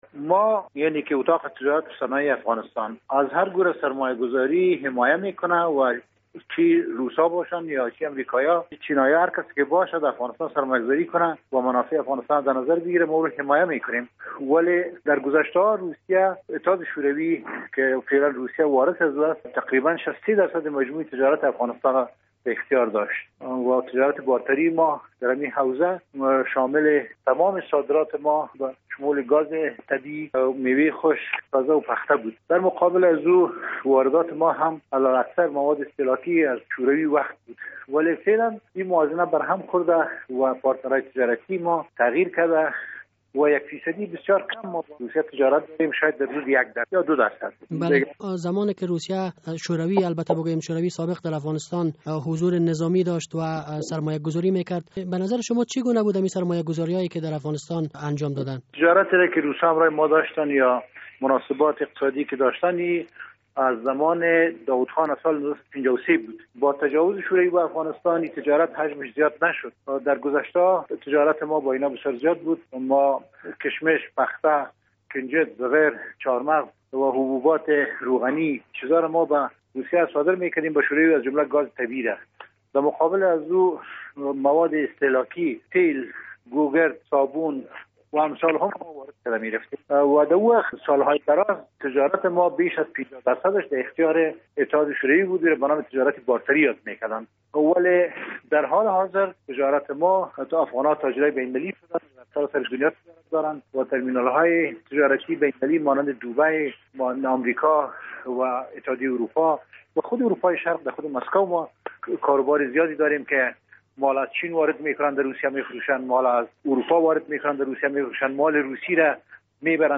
مصاحبه: اتاق تجارت و صنایع از سرمایه گذاری های روسیه استقبال می کند